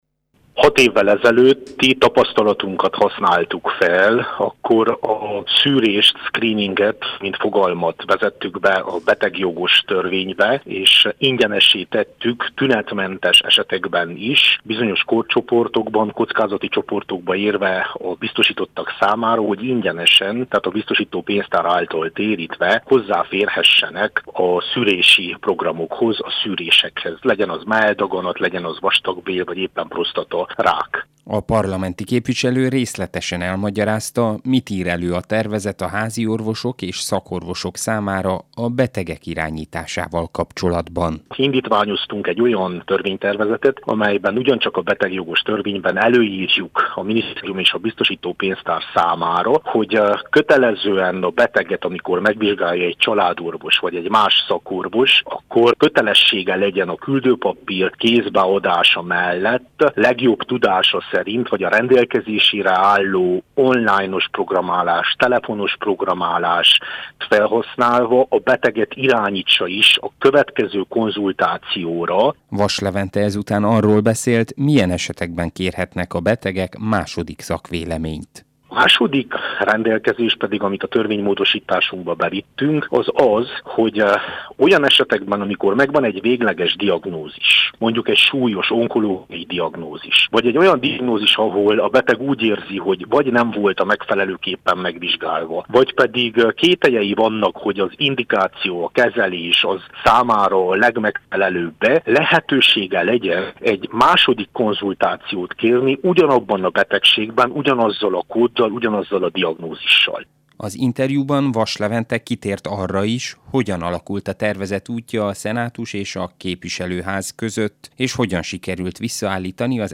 Vass Levente egészségügyi szakpolitikust, az RMDSZ parlamenti képviselőjét kérdezte